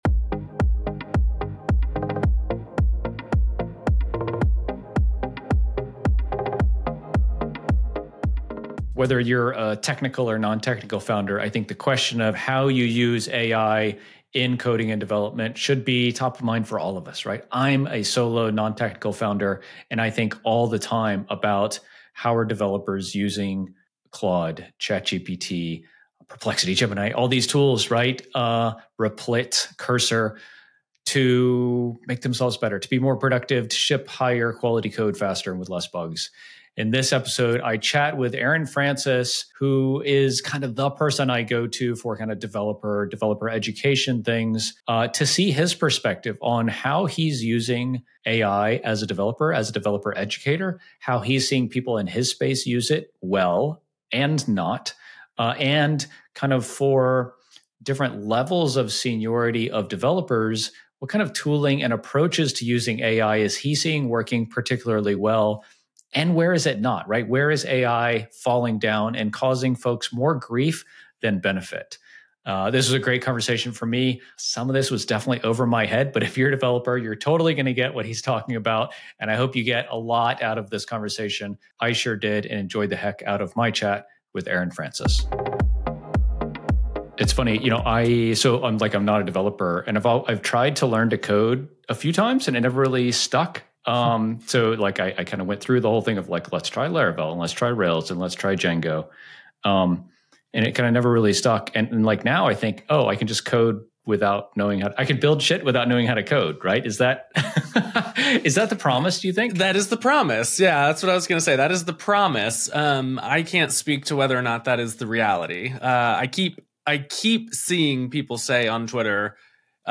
Whether you're trying to speak your developer's language or just want to ensure your company is using AI effectively, this conversation will give you the insights you need.